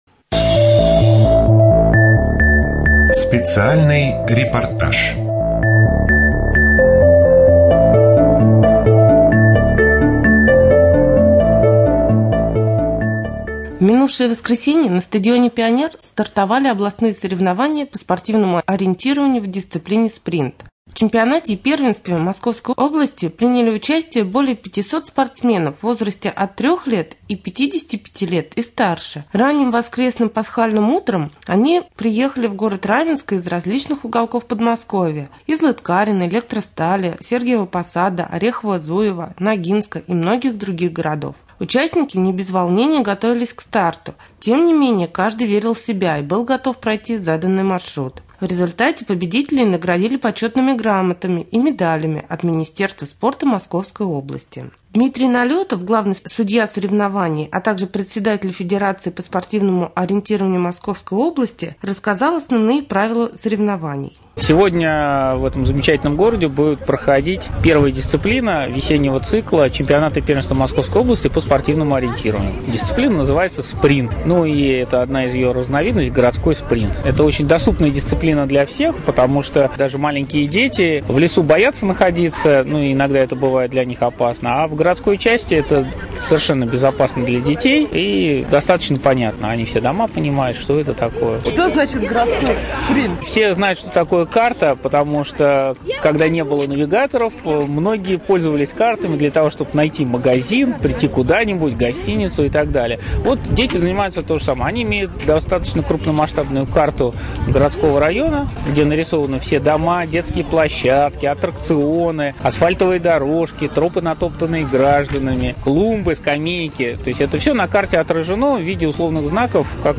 24.04.2014г. в эфире раменского радио - РамМедиа - Раменский муниципальный округ - Раменское
3.Рубрика «Специальный репортаж». На стадионе «Пионер» стартовали областные соревнования по спортивному ориентированию.